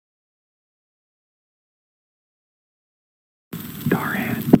saying darhan quiet